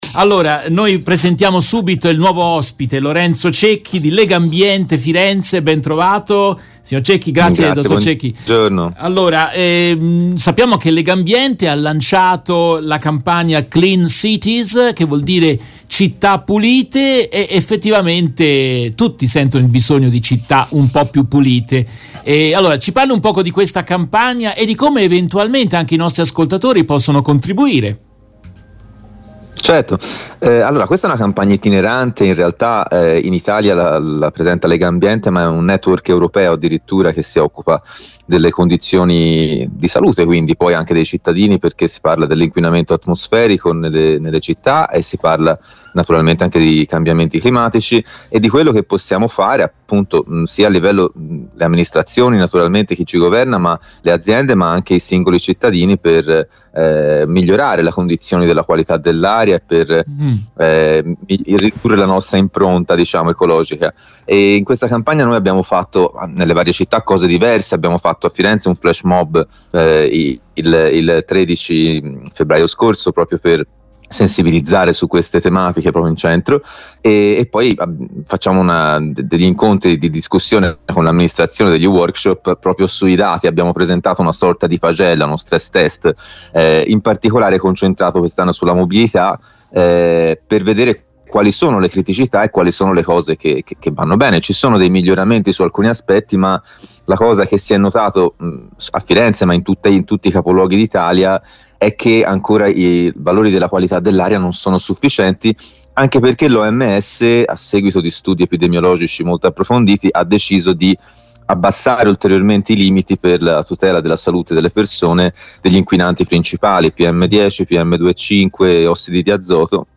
In questa trasmissione in diretta del 22 febbraio 2022